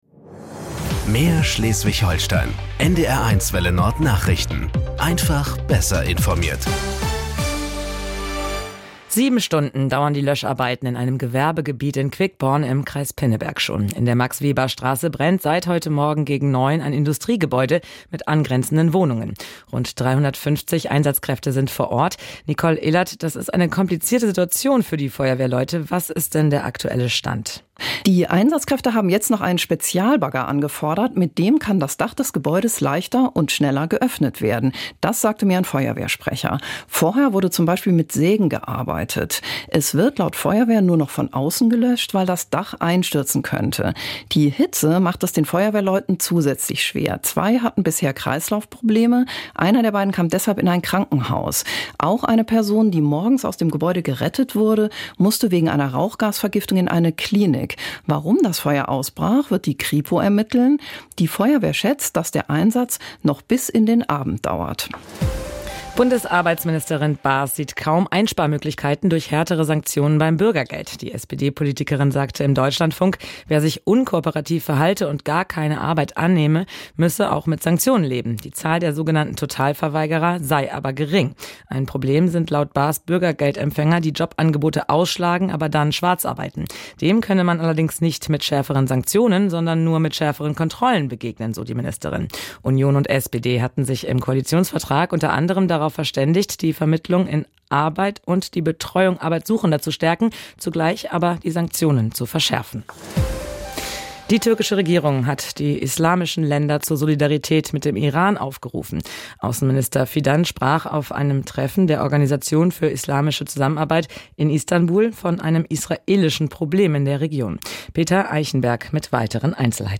… continue reading 3 episodes # Tägliche Nachrichten # Nachrichten # NDR 1 Welle Nord